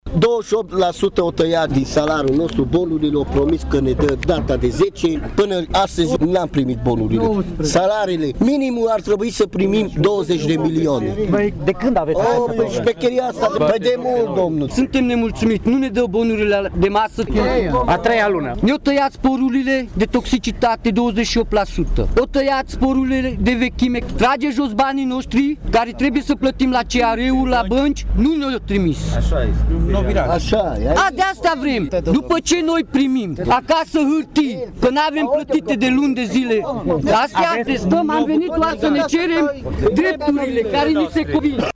Circa 150 de angajați au huiduit și au strigat ”Hoții!”, cerând urgent rezolvarea doleanțelor: